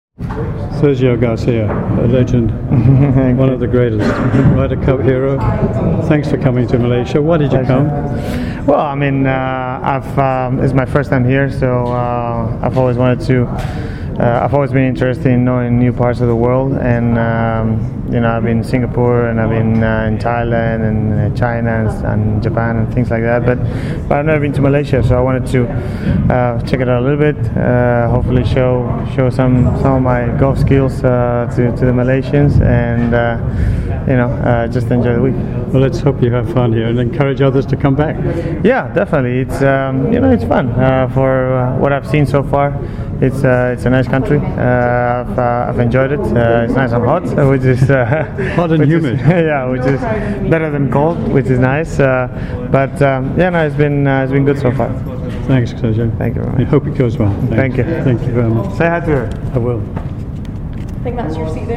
MGTA interviews Sergio Garcia